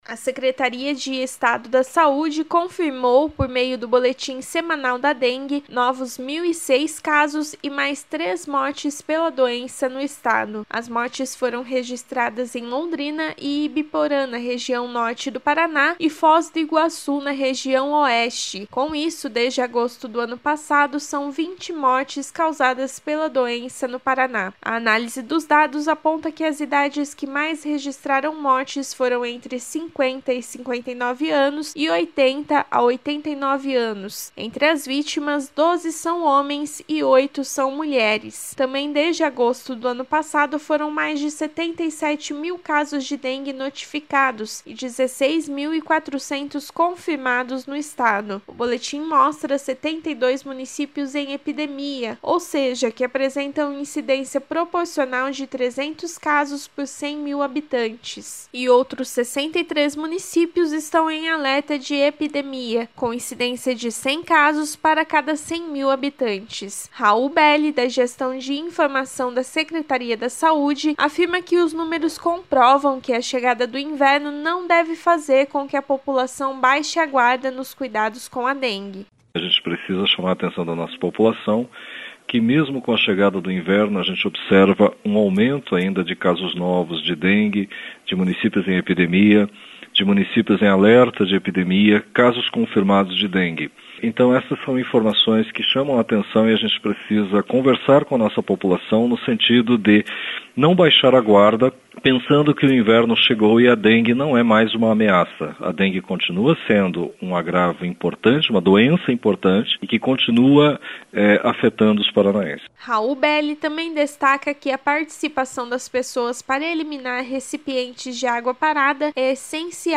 Mais detalhes na programação da Rádio Cultura AM 930